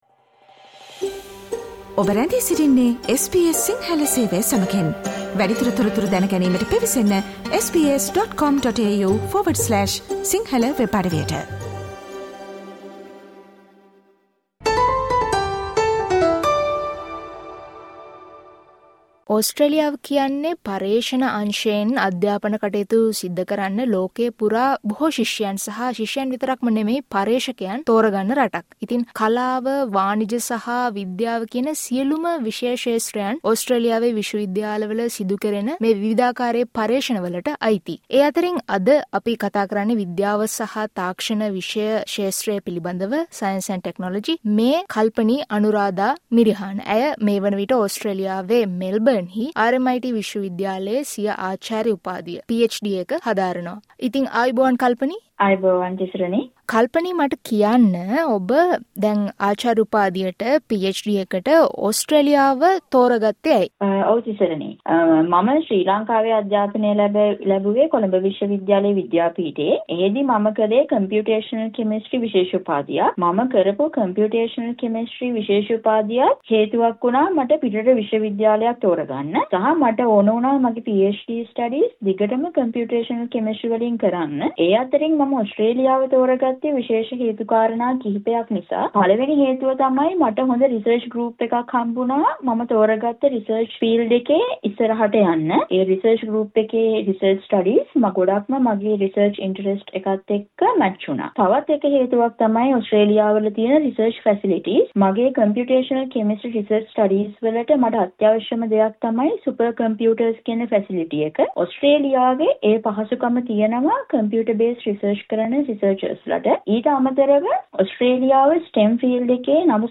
විද්‍යා හා තාක්ෂණ අංශයෙන් සිය ආචාර්ය උපාධිය සඳහා ඕස්ට්‍රේලියාව තෝරා ගත් ශිෂ්‍යාවියක මේ සම්බන්ධයෙන් කළ අදහස් දැක්වීමකට සවන් දෙන්න.